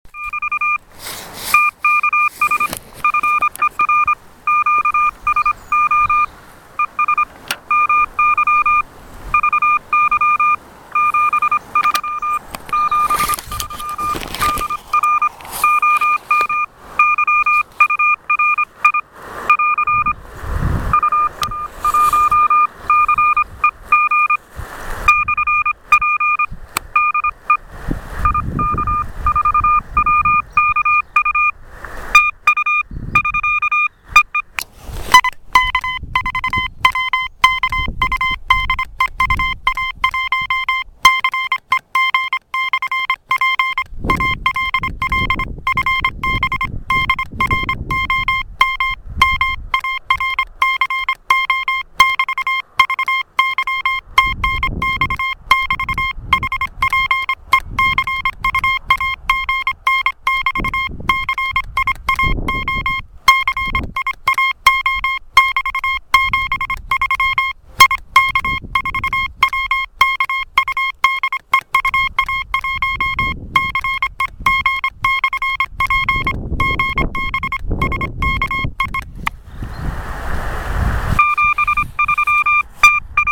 ТЕМА: QSO на самоделках